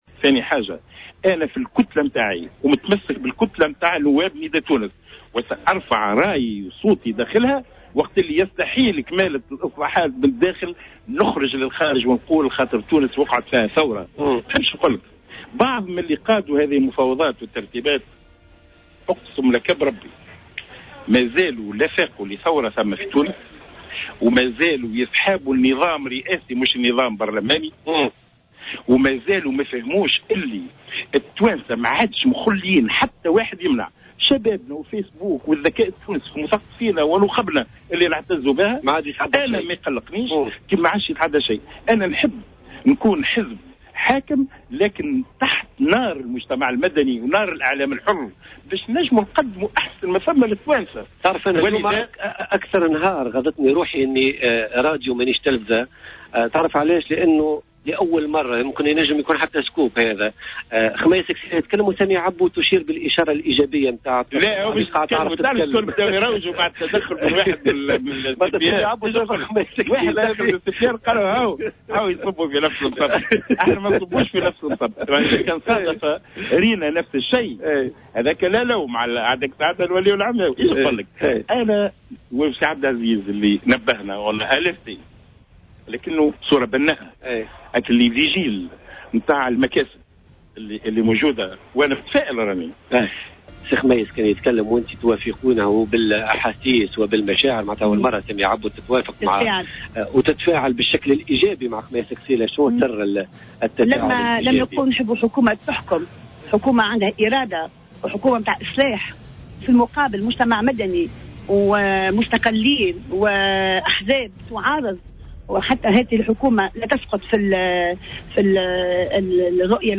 وأكد قسيلة، لدى استضافته في استوديو خارجي من مجلس نواب الشعب إلى جانب سامية عبو، أن على الحزب الحاكم أن يراعي ذكاء التونسيين الواقفين بالمرصاد لكل الإخلالات، مشددا على ضرورة أن يتعامل النداء مع " نار المجتمع المدني والإعلام الحر".